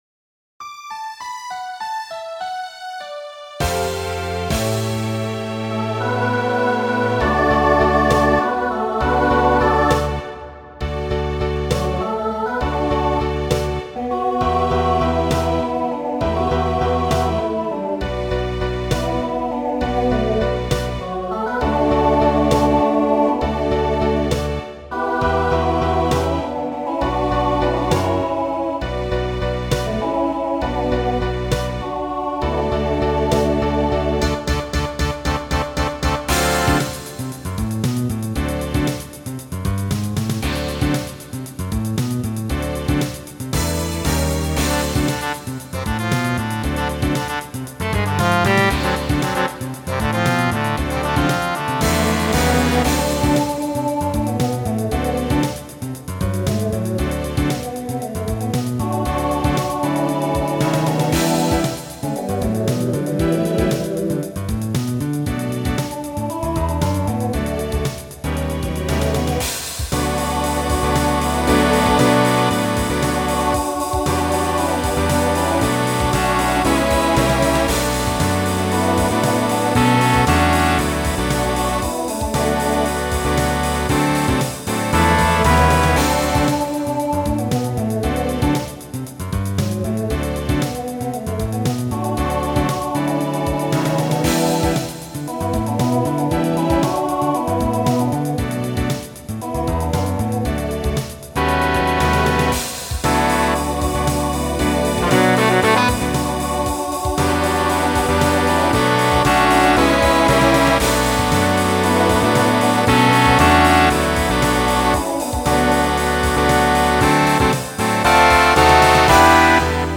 Voicing SATB Instrumental combo Genre Pop/Dance , Swing/Jazz
Mid-tempo